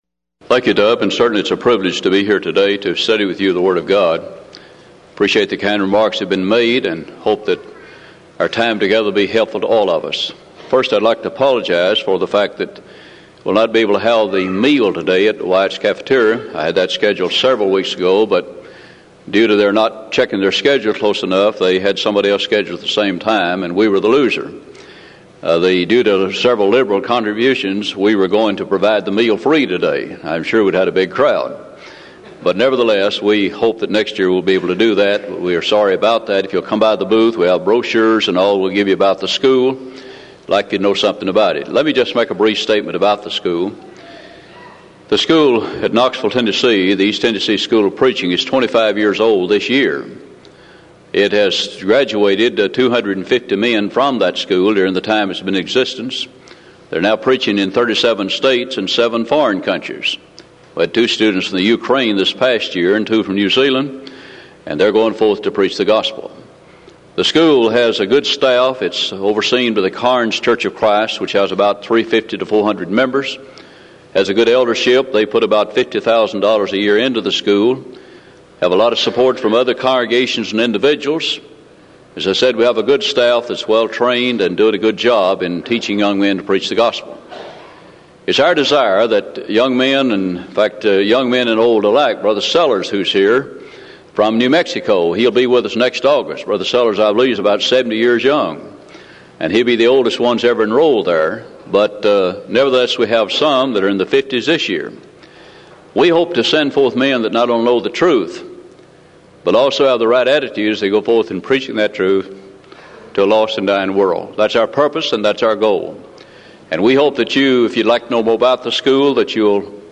Event: 1996 Denton Lectures